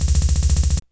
drums05.wav